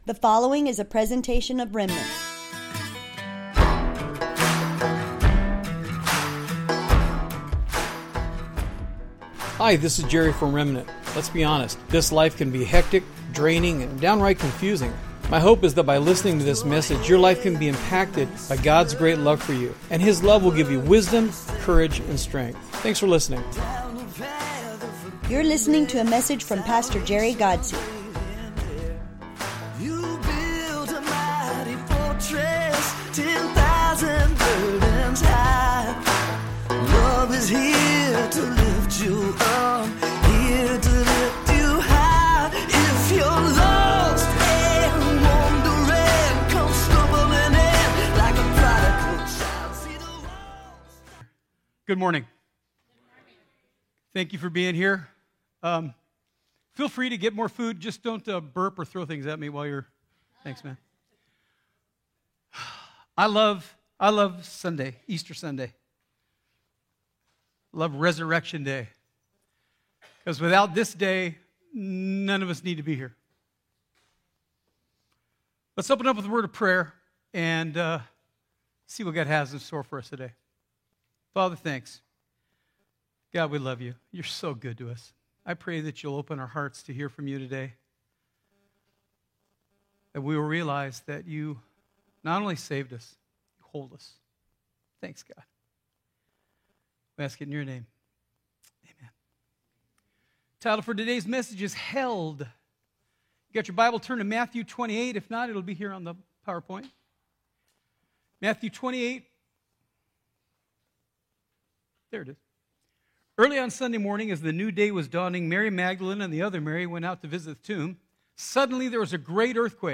Welcome to the livestream of our Easter Service at Remnant Church in Imperial Valley, CA.